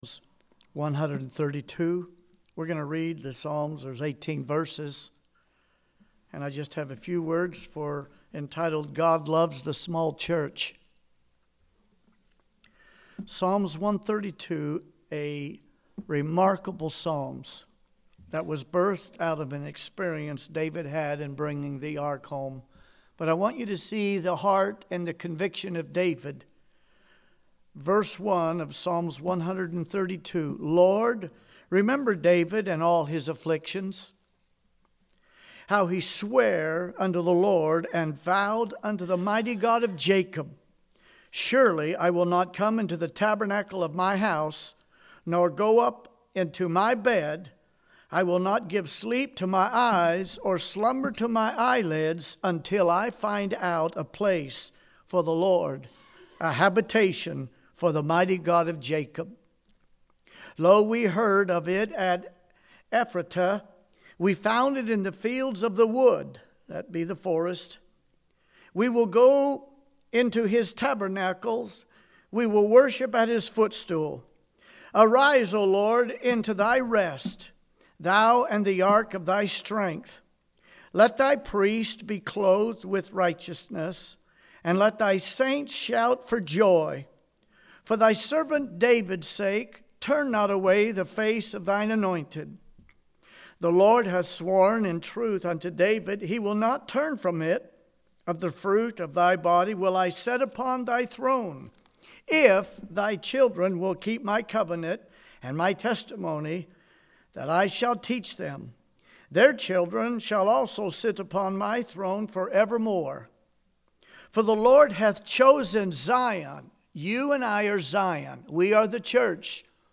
Evening Sermons